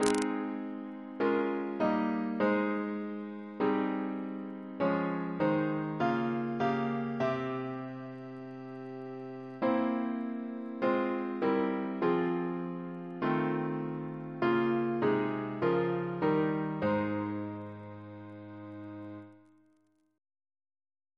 Double chant in A♭ Composer